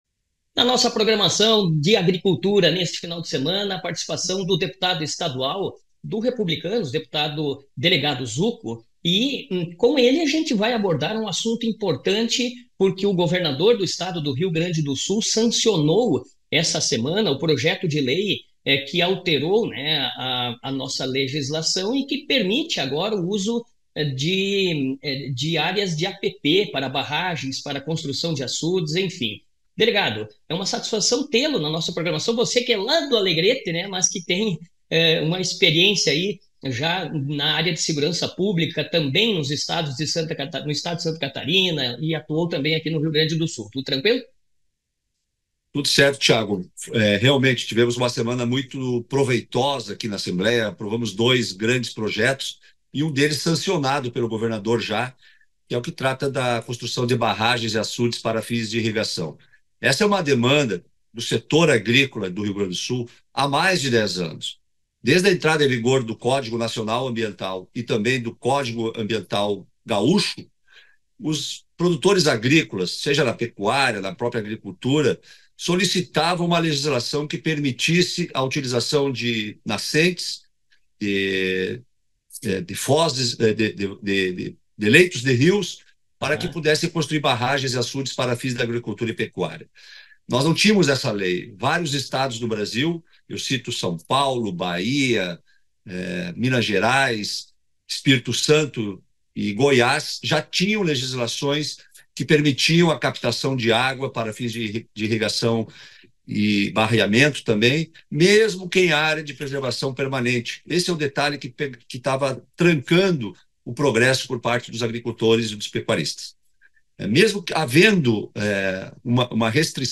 Reportagem
Deputado estadual , Delegado Zucco, fala sobre a aprovação por parte do Governador do Estado, Eduardo Leite, que sancionou o projeto de lei que regulariza as áreas de preservação permanentes para fins de irrigação no estado.